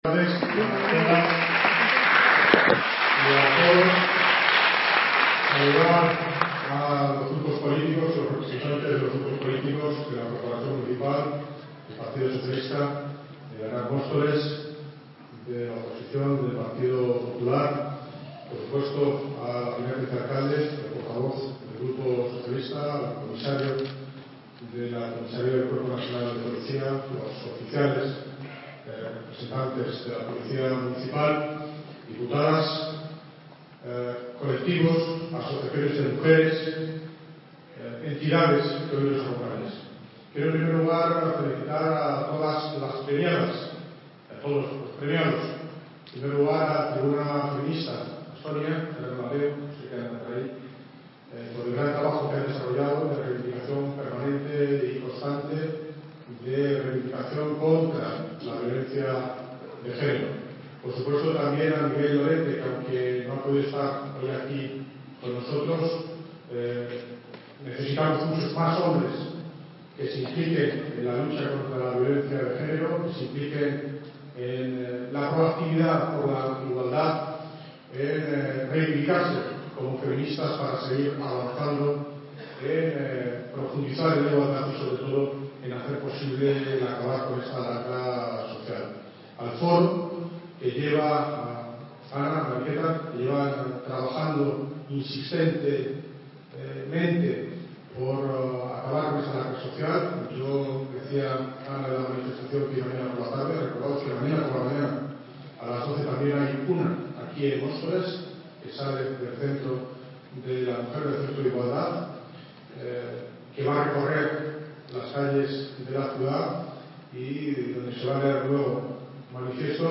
Audio - David Lucas (Alcalde de Móstoles) Discurso entrega de Premios
Audio - David Lucas (Alcalde de Móstoles) Discurso entrega de Premios.mp3